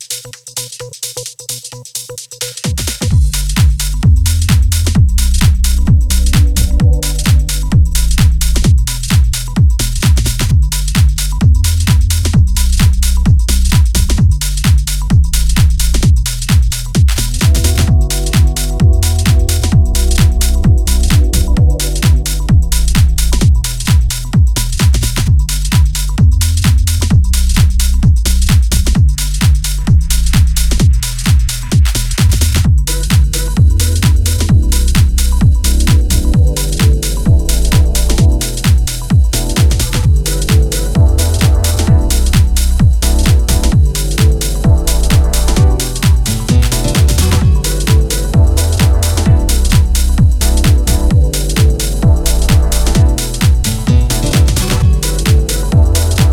Featuring four hypnotic tracks.